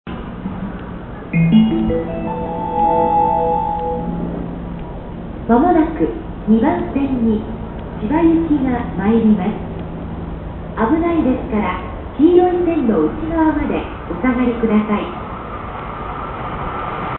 接近放送には、５打点メロディが流れる。
接近放送A線千葉方面A線の接近放送です。
改良前は、種別と行き先の間がほとんどなく不自然な放送でした。
*実際は２回流れます。(A・B線共に平井駅で収録)*
for-chiba.mp3